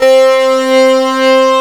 HARD LEAD.wav